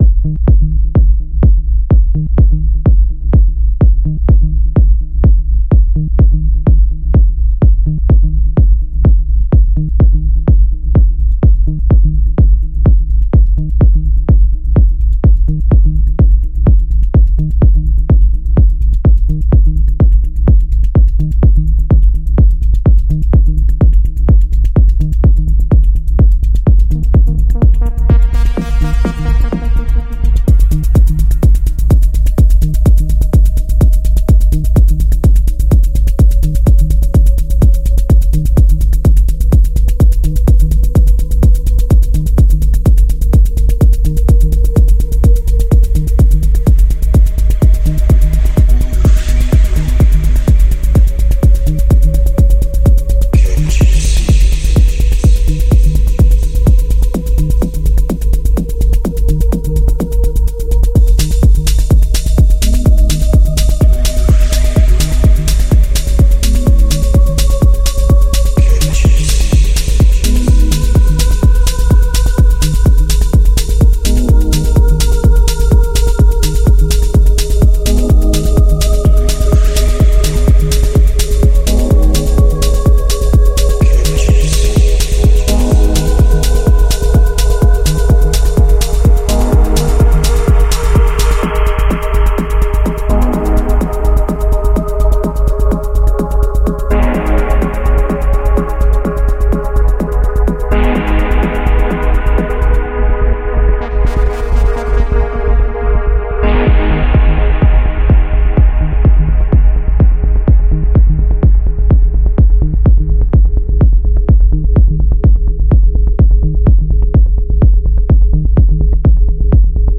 Жанр: Жанры / Техно